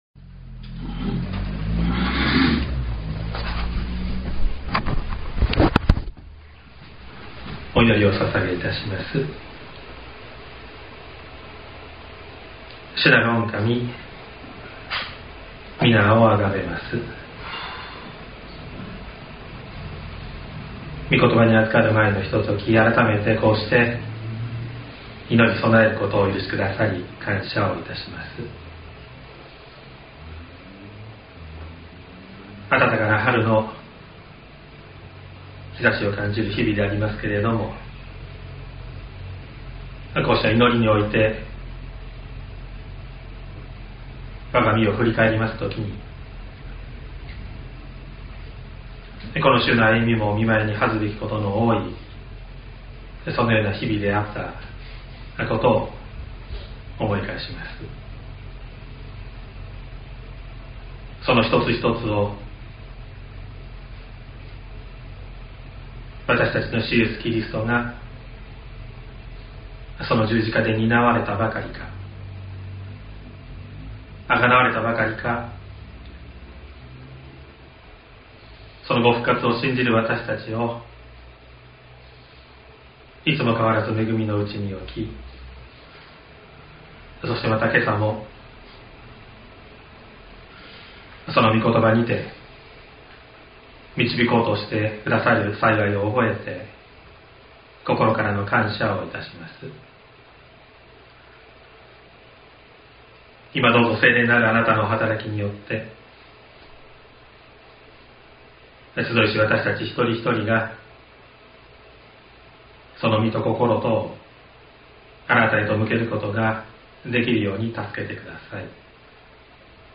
2026年04月26日朝の礼拝「先へと行かれる主イエス」西谷教会
説教アーカイブ。
音声ファイル 礼拝説教を録音した音声ファイルを公開しています。